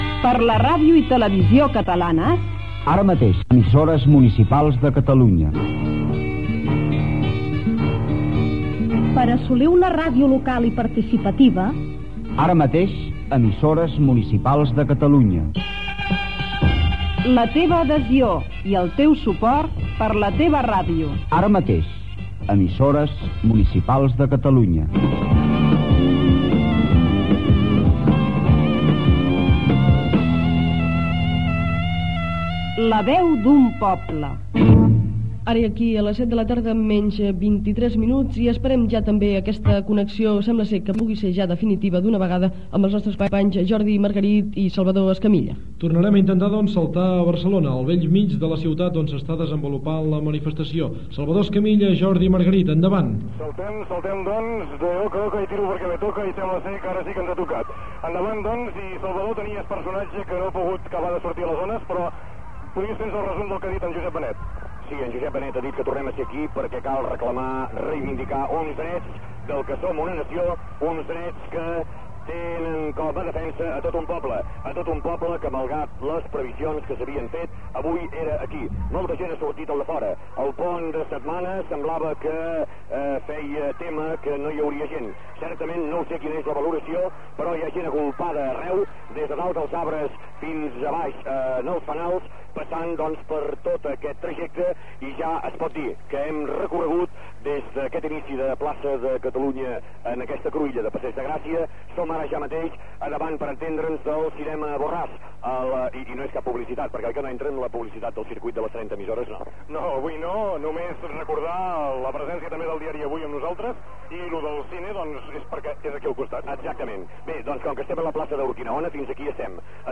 Transmissió conjunta de les EMUC de la manifestació de la Diada de l'11 de setembre de 1981, a Barcelona
Informatiu
30 emissores municipals connectades.